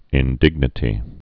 (ĭn-dĭgnĭ-tē)